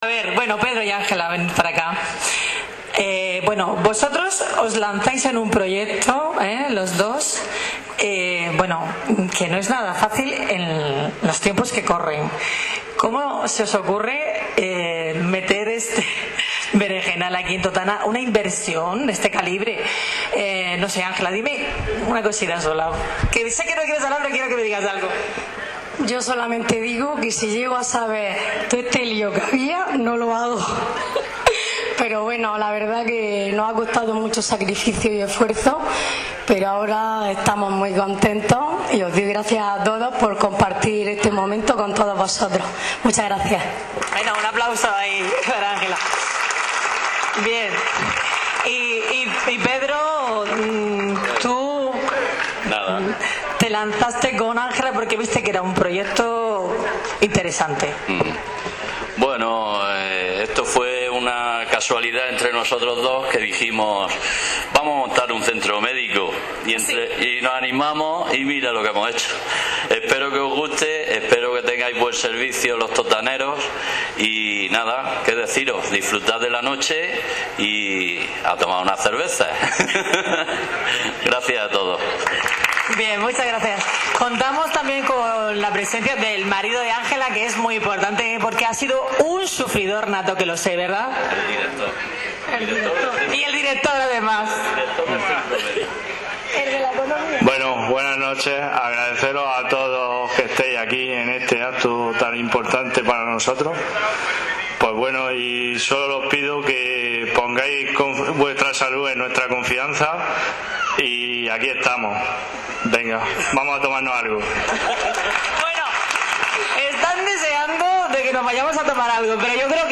Inauguración Centro Médico El Edén